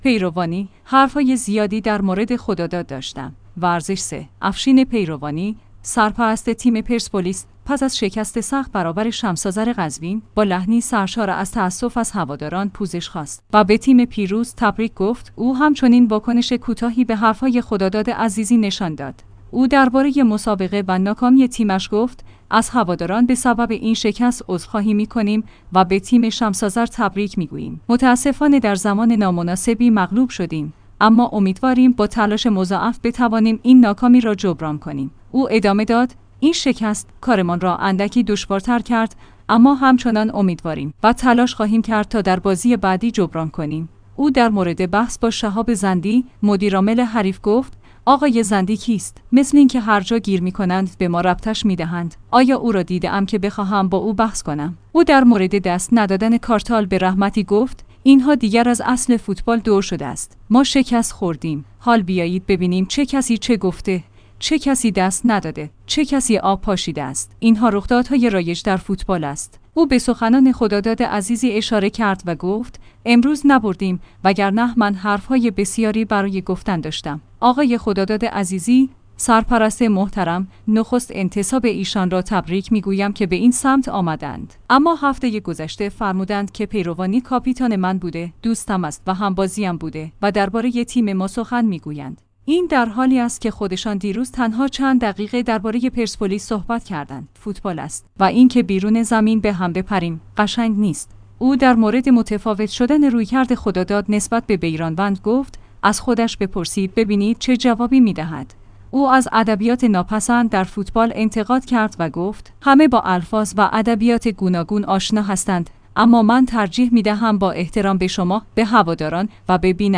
ورزش 3/افشین پیروانی، سرپرست تیم پرسپولیس، پس از شکست سخت برابر شمس‌آذر قزوین، با لحنی سرشار از تأسف از هواداران پوزش خواست و به تیم پیروز تبریک گفت؛ او همچنین واکنش کوتاهی به حرف‌های خداداد عزیزی نشان داد.